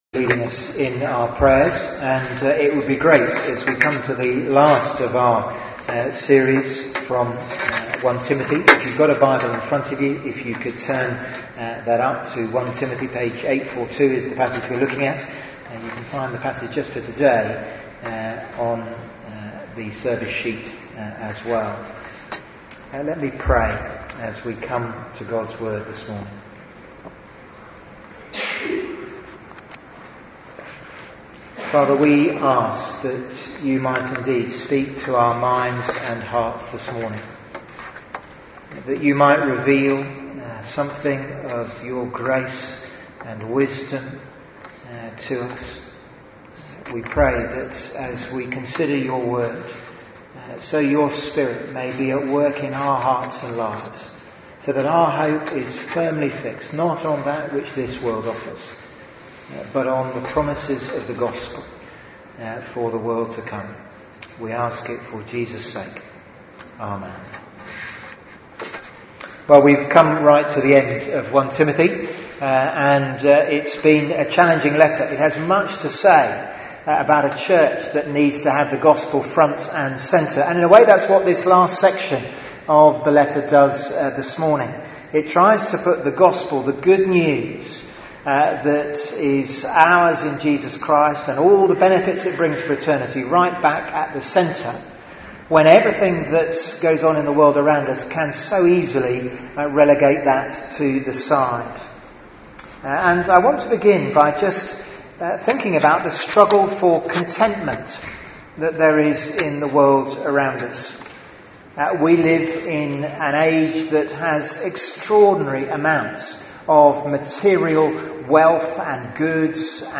Theme: Living for eternity Sermon